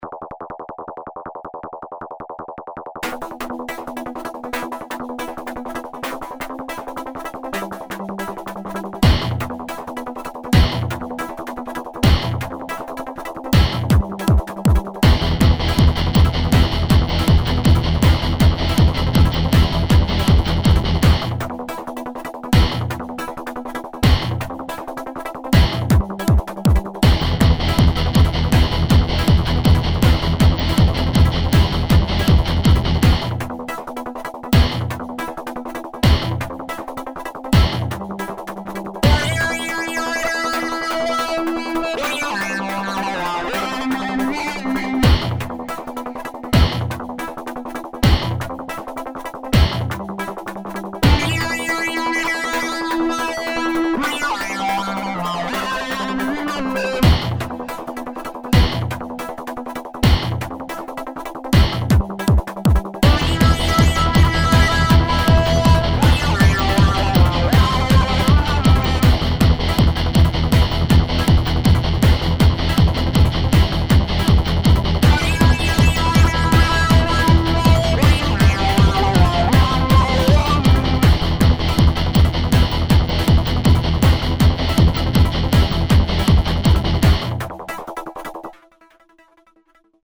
Тока эта... Концовка какаято резковатая имхо. Раз, и оборвалось.